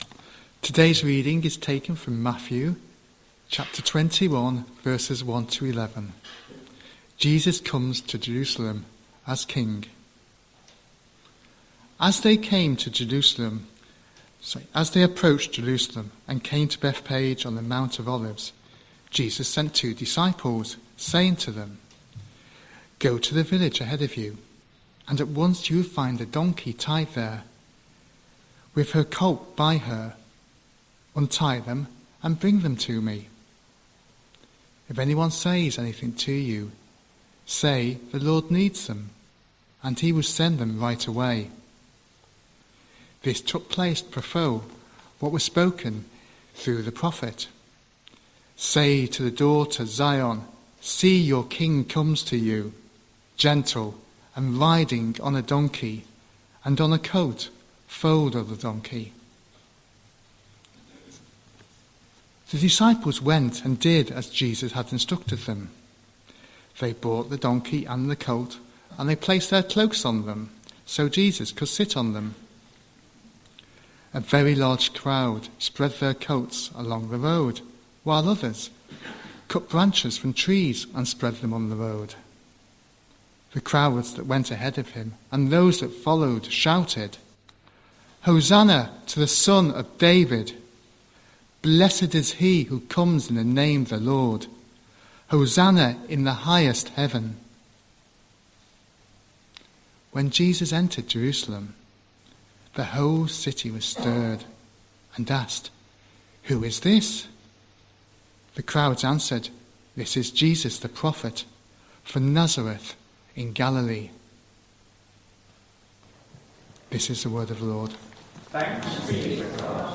29th March 2026 Sunday Reading and Talk - St Luke's